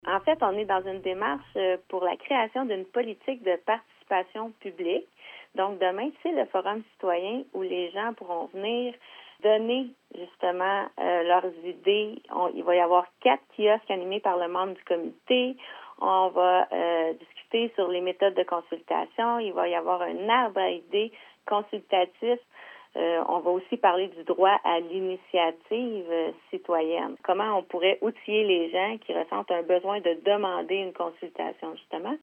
Tatiana Contreras, mairesse de Bromont.